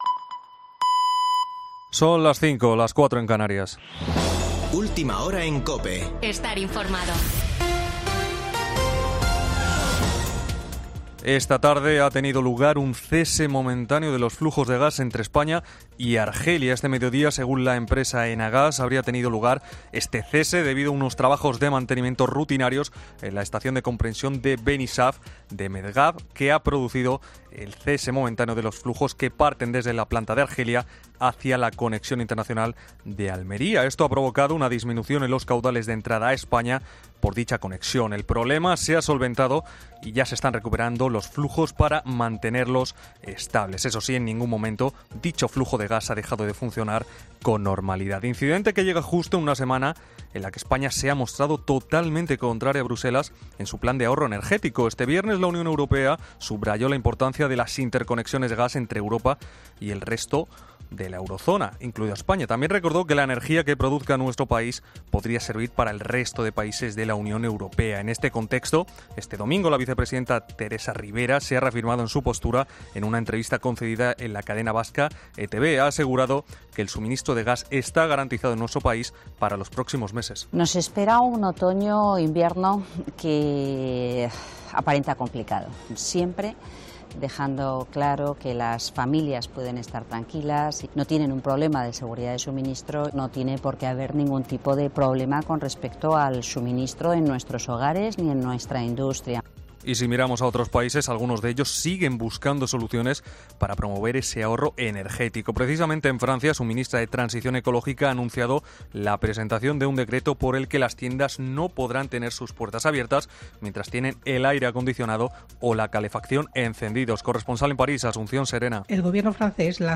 AUDIO: Boletín de noticias de COPE del 24 de julio de 2022 a las 17:00 horas